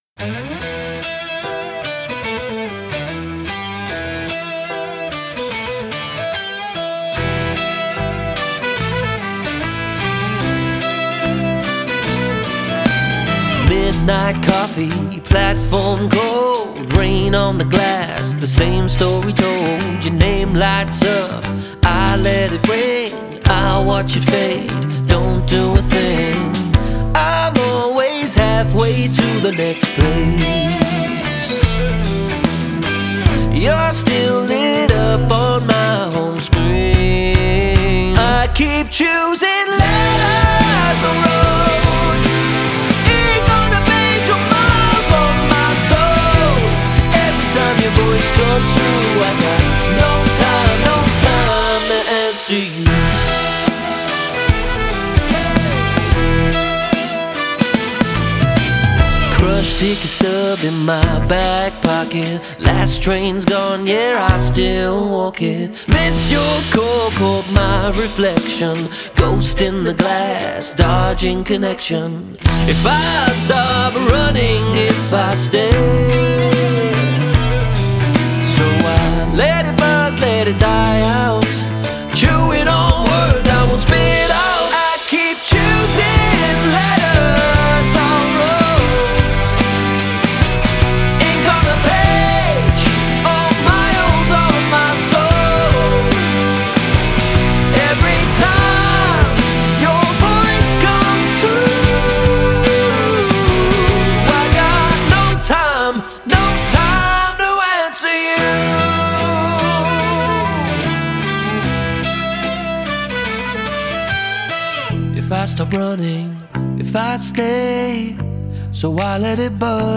indie atmosférico
composição indie temperamental
Vocalist